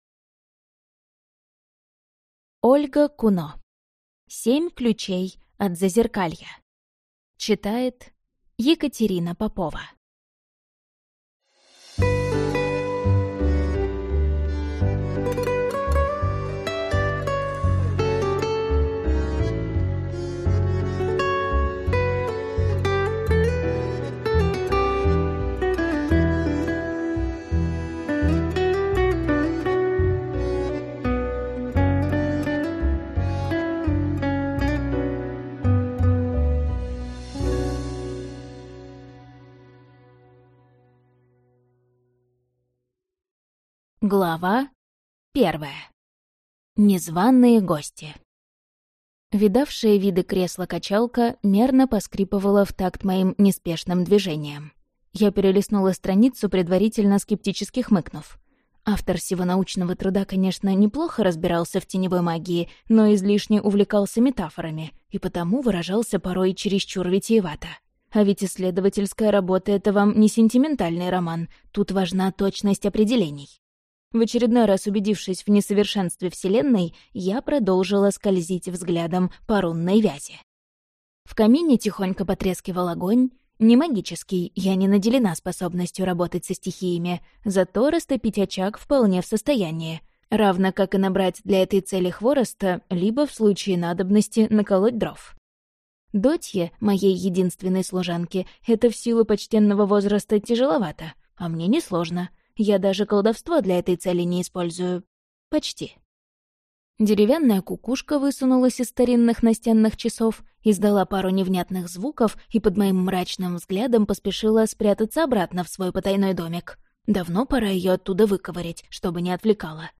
Аудиокнига Семь ключей от зазеркалья - купить, скачать и слушать онлайн | КнигоПоиск